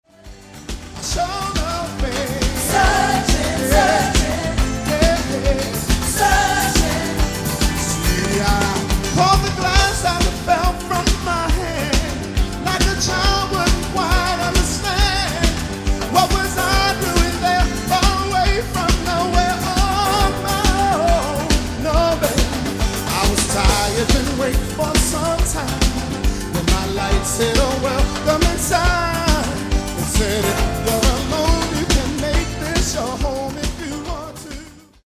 Genere:   RnB | Soul | Dance